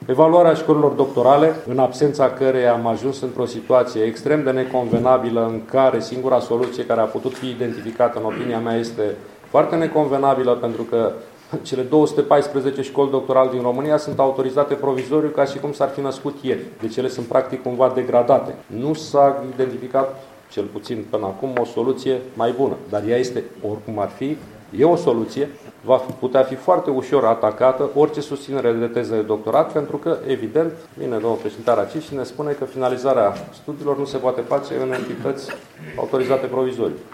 Semnalul de alarmă a fost tras astăzi, la Tîrgu-Mureș, de președintele Consiliului Național al Rectorilor, fostul ministru al Educației, Sorin Câmpeanu.